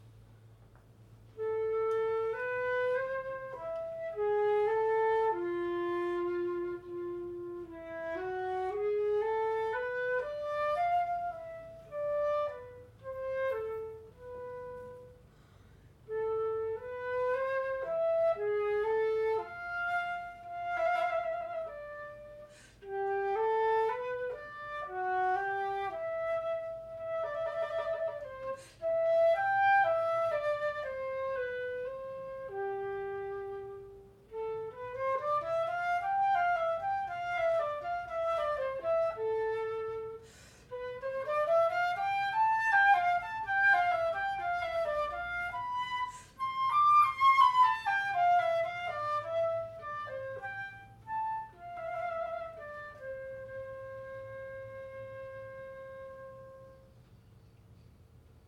Solo
Extrait lors d’une lecture & concert, Galerie du Docteur Bonnet, Montpellier, décembre 2022